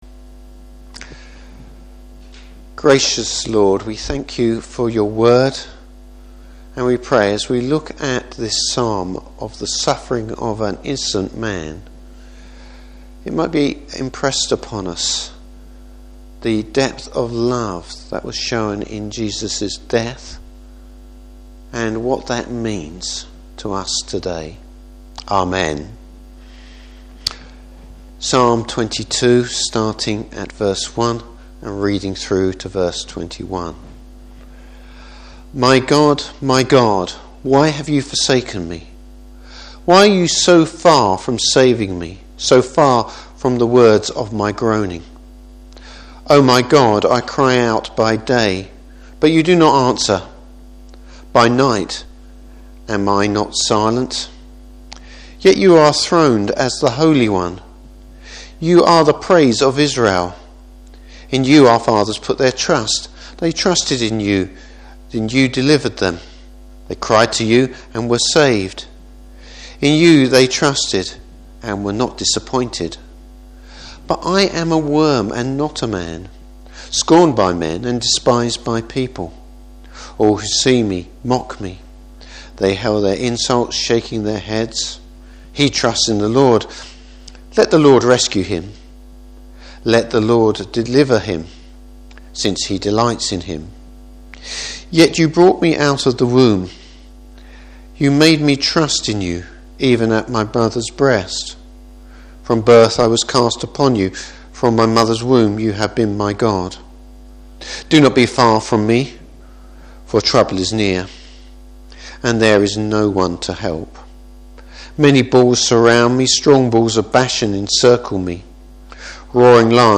Service Type: Good Friday Service.